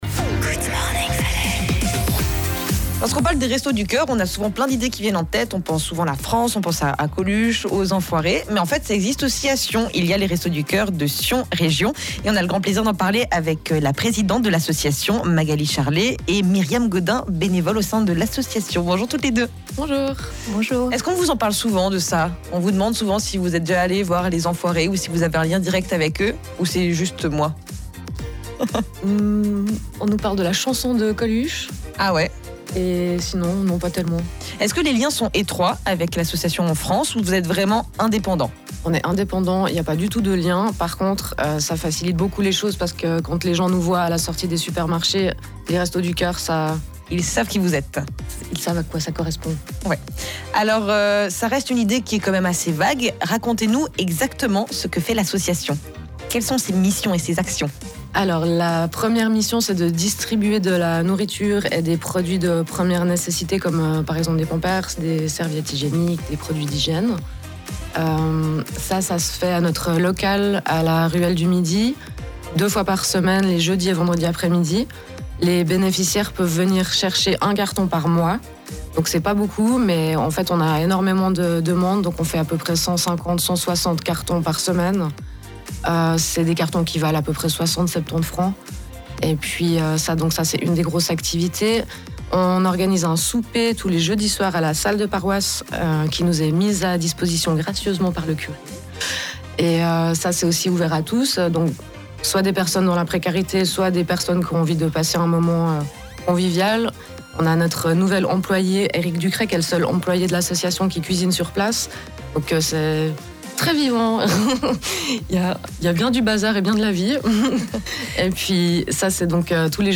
Le 14 novembre dernier, les Restos du Coeur étaient invités à la matinale de Rhône FM « Good morning Valais », une occasion de présenter nos activités et le témoignage précieux de l’une de nos bénévoles 🙂
ITW-1-Restos-du-Coeur.mp3